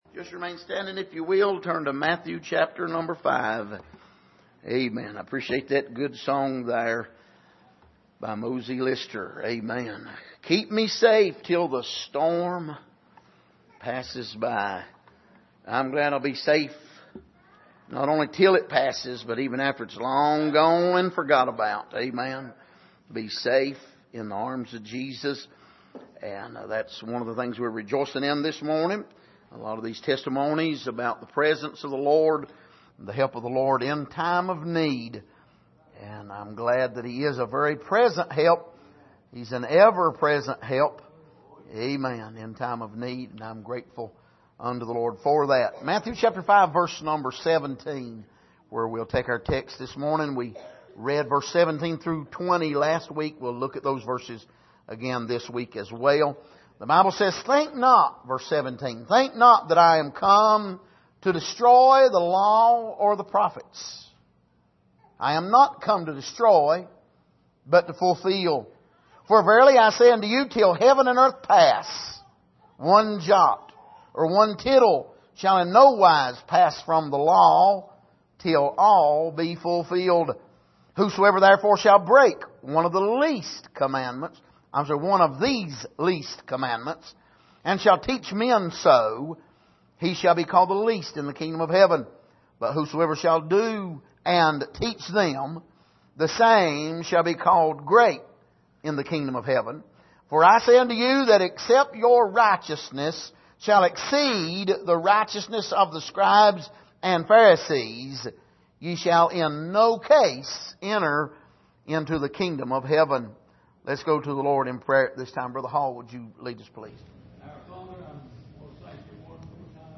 Passage: Matthew 5:21-26 Service: Sunday Morning